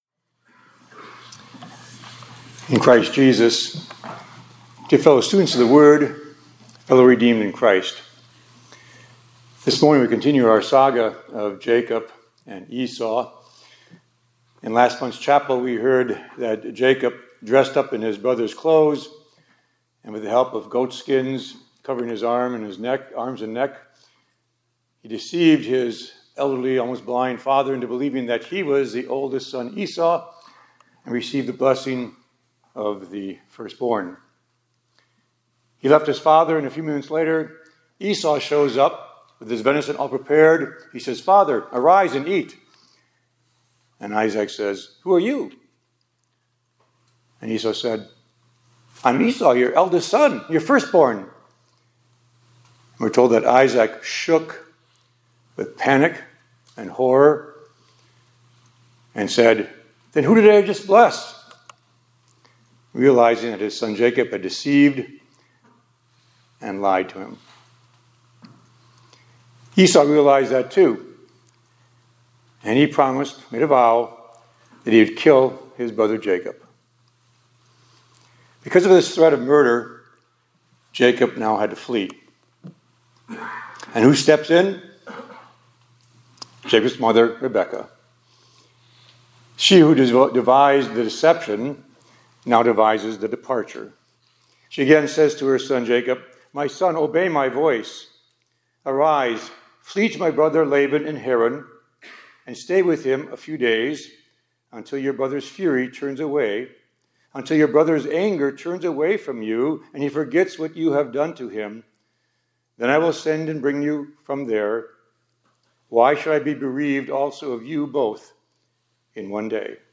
2025-03-03 ILC Chapel — God Uses Exile for Our Good – Immanuel Lutheran High School, College, and Seminary